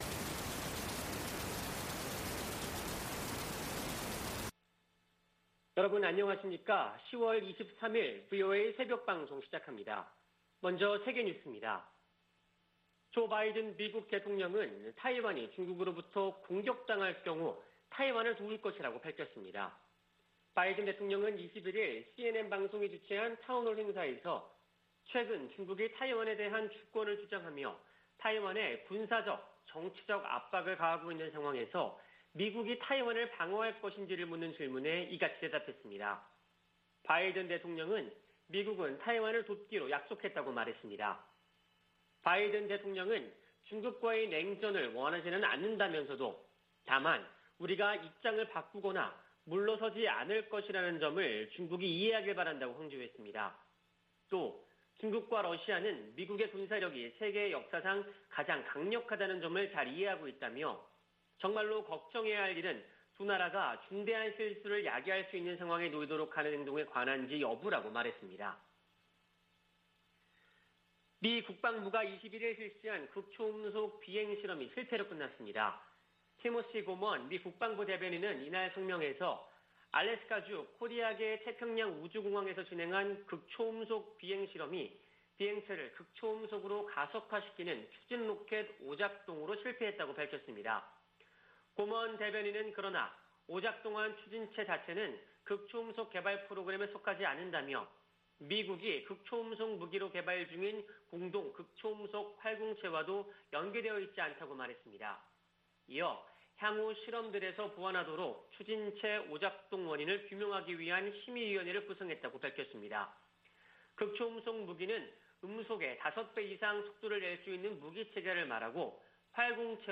VOA 한국어 '출발 뉴스 쇼', 2021년 10월 23일 방송입니다. 유엔 안보리가 북한 SLBM 규탄 성명을 내는데 실패했습니다. 북한이 가능한 모든 영역에서 핵 개발에 전력을 다하고 있다고 국제원자력기구(IAEA) 사무총장이 지적했습니다.